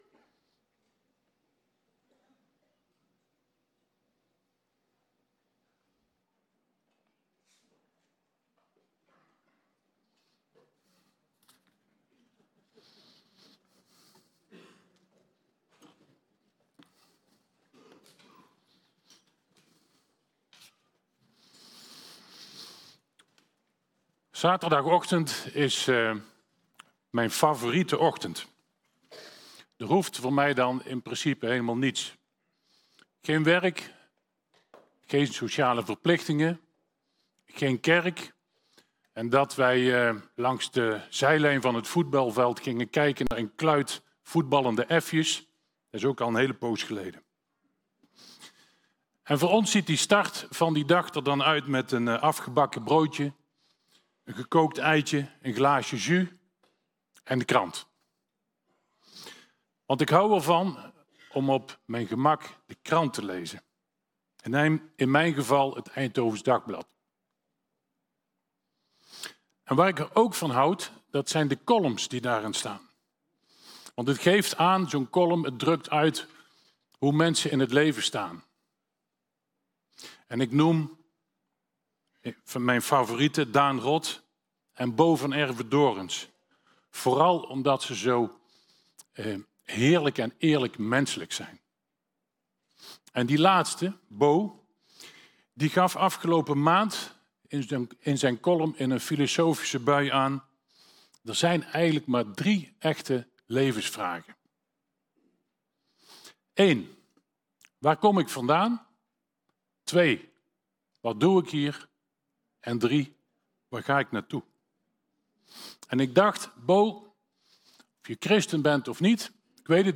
Toespraak tijdens doopdienst spreker